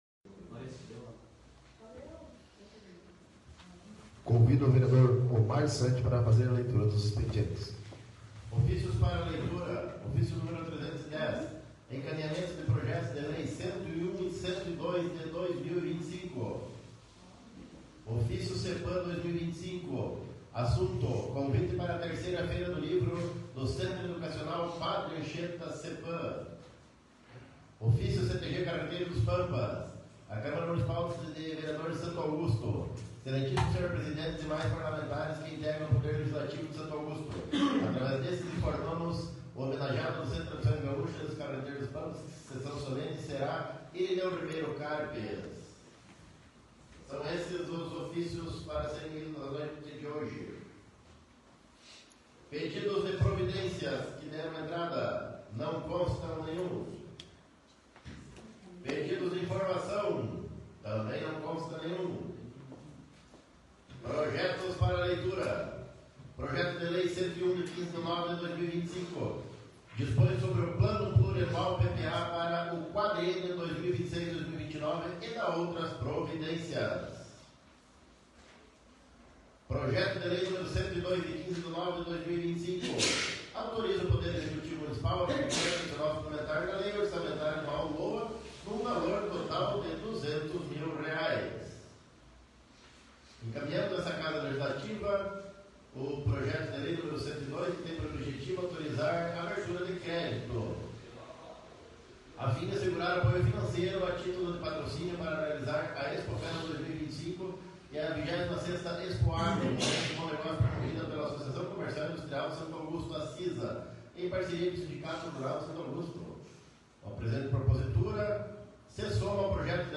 31ª Ordinária da 1ª Sessão Legislativa da 16ª Legislatura